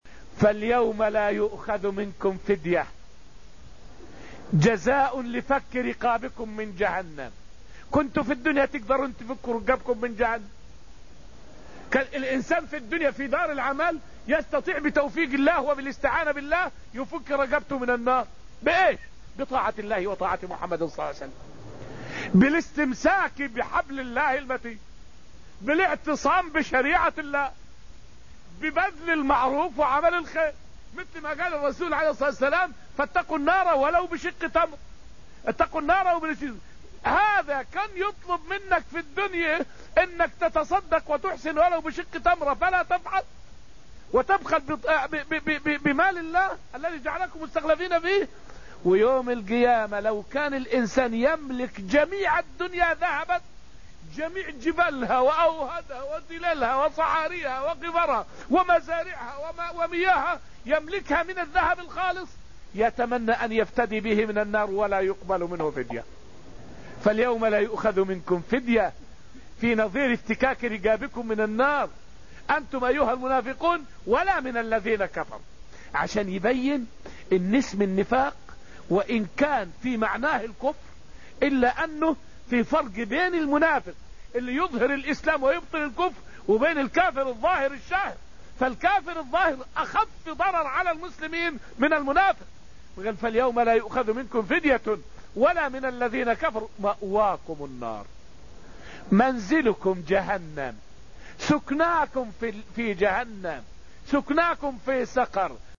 فائدة من الدرس العاشر من دروس تفسير سورة النجم والتي ألقيت في المسجد النبوي الشريف حول هل كل من طلب الدنيا يكون مذمومًا في طلبه لها؟